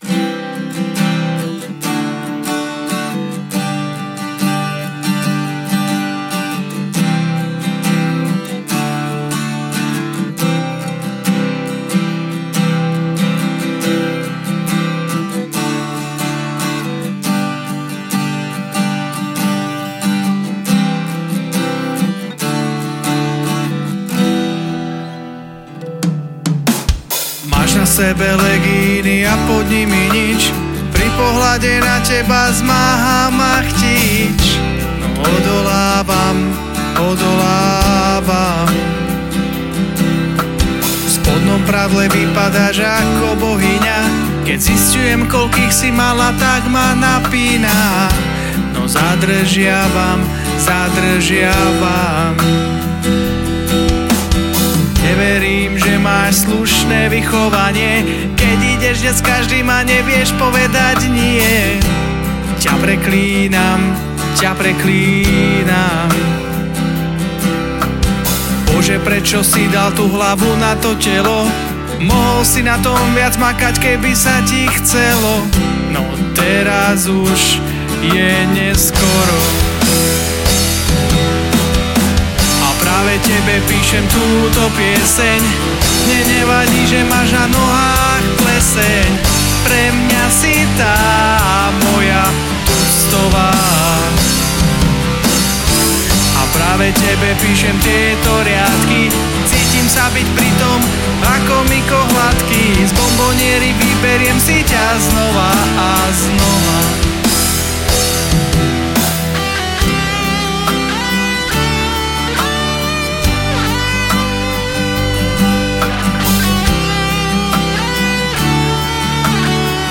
Žánr: Rock
gitara, spev
basgitara